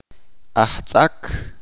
[ʔat ɓan.’lah ’ti.ɓah] adjetivo Blessed